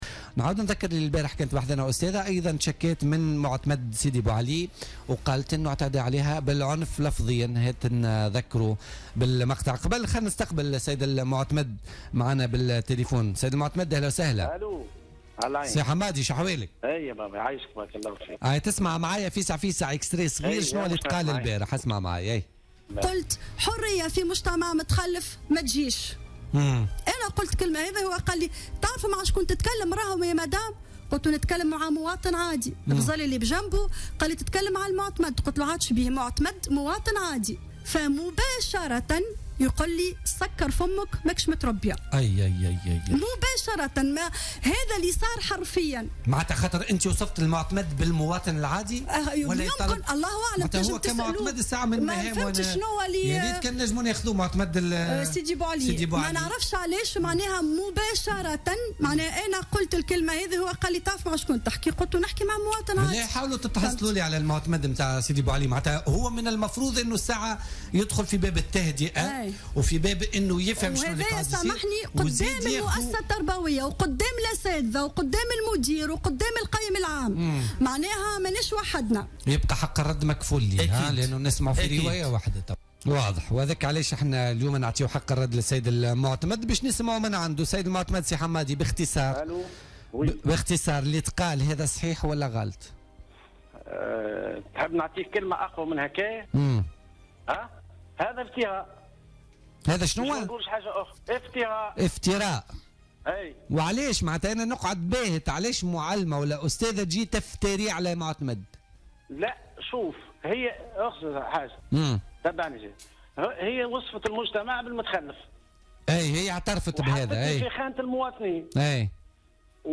أكد معتمد سيدي بوعلي في مداخلة له في بوليتيكا اليوم الأربعاء 23 نوفمبر 2016 أن اتهامه من قبل أستاذة بمدرسة اعدادية بالتهجم عليها وشتمها مجرد افتراء عليه ولا أساس له من الصحة.